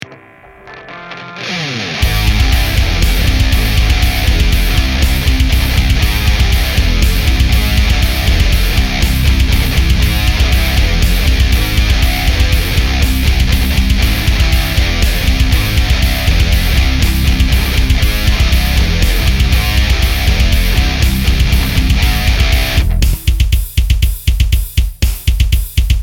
My latest attempt at getting good results with vst amp sims and impulses.